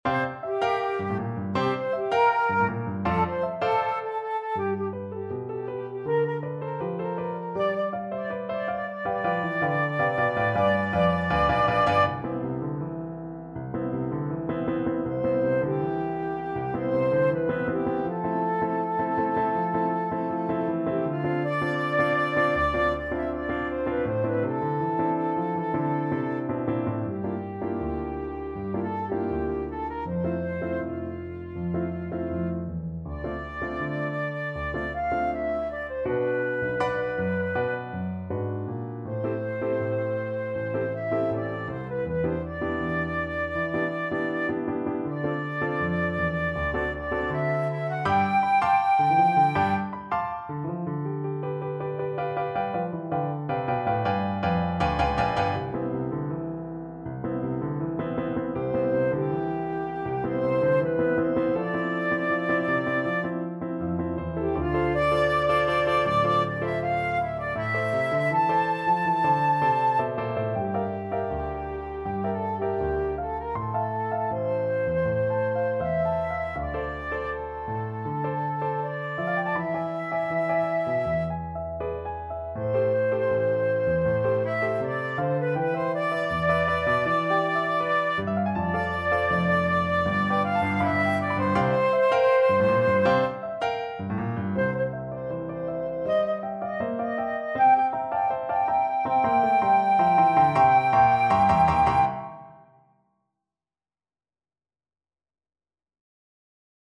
2/2 (View more 2/2 Music)
= c. 80 Muy Rapido!
Flute  (View more Intermediate Flute Music)
Jazz (View more Jazz Flute Music)